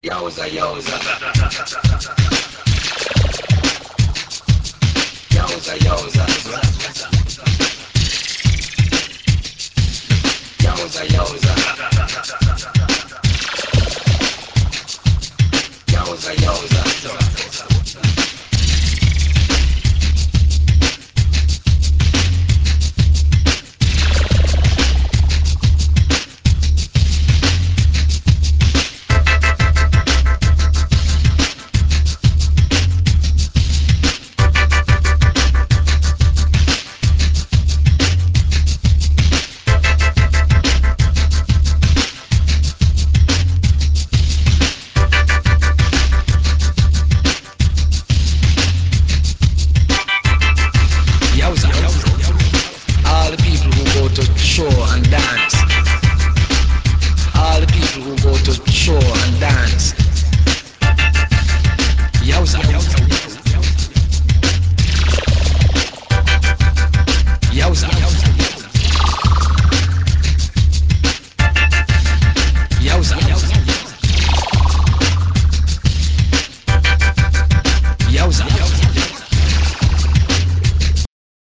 Dub, Downbeat und Drum&Bass